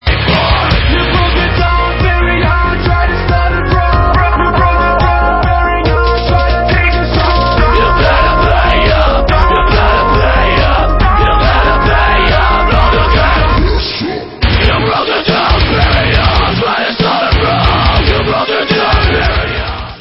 POST-HARDCORE WITH ELECTRONIC APPROACH